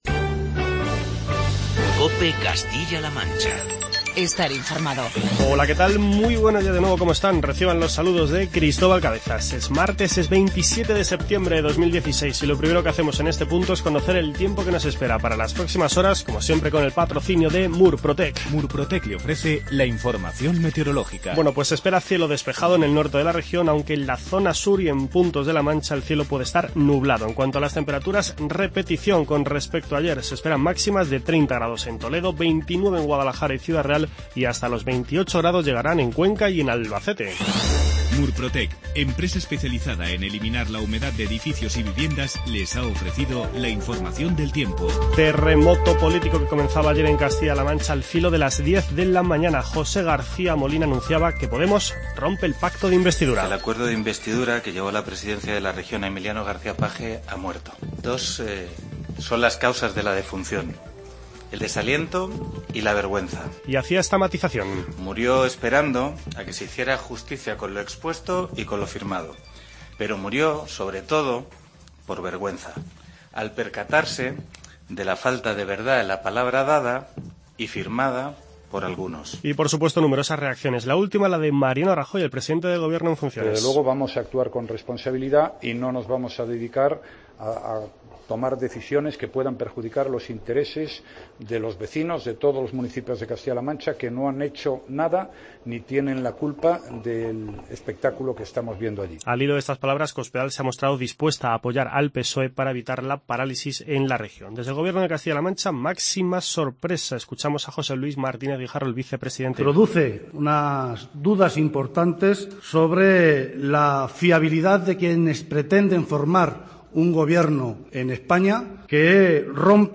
Terremoto político en Castilla-La Mancha tras la decisión de Podemos de romper el acuerdo con el Partido Socialista de Emiliano García-Page. Hacemos un recorrido por las principales declaraciones que nos ha dejado esta noticia con las palabras de José García Molina, Mariano Rajoy, José Luis Martínez Guijarro, Francisco Cañizares, Cristina Maestre, José Manuel Caballero y Carlos Cotillas. En cuanto a otros temas, nos marchamos hasta Guadalajara ya que Javier Rosell, director general de Empresas, ha visitado el Centro de Innovación y Empresas de la capital alcarreña.